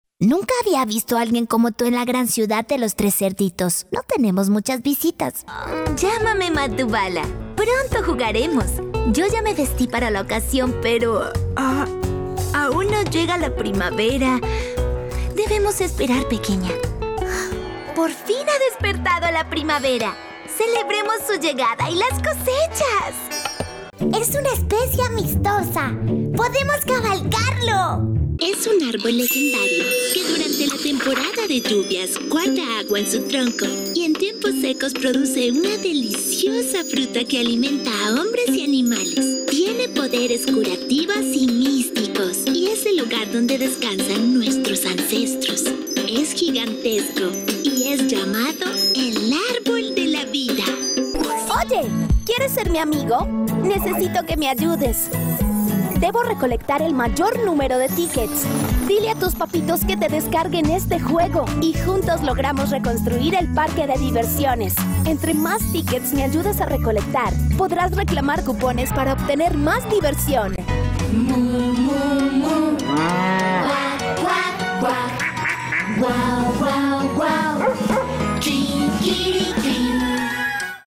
Espanhol (latino-americano)
Animação
Cabine de gravação profissional isolada